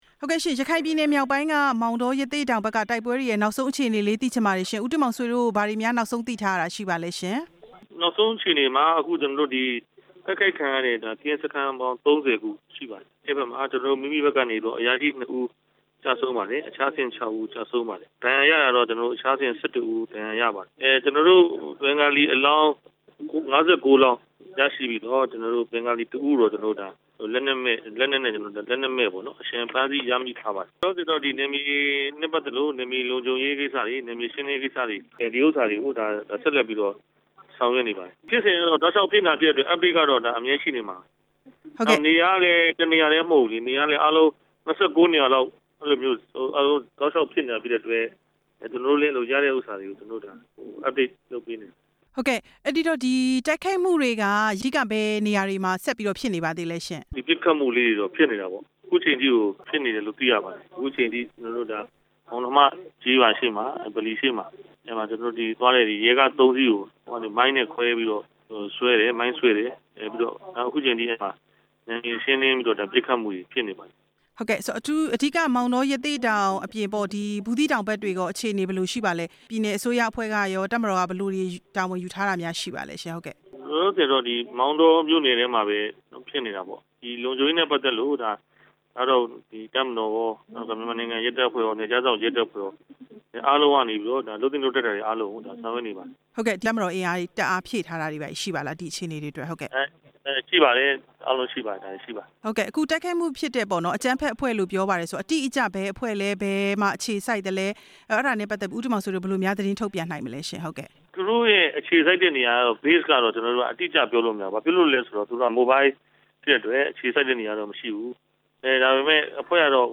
မောင်တော အကြမ်းဖက်မှုအကြောင်း ရခိုင်ပြည်နယ်အစိုးရအဖွဲ့ အတွင်းရေးမှူးနဲ့ မေးမြန်းချက်